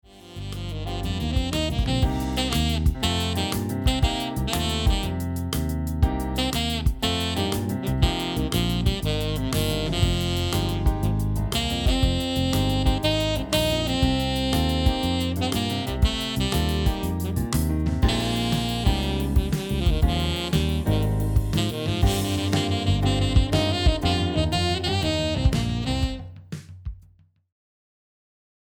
Range: low D to palm key E.